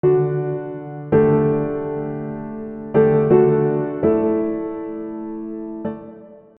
Tom Petty’s “Free Fallin’” sus4 chords to achieve that sense of openness and ambiguity in the intro. ‍
Chords: D - Dsus4 - D - Asus4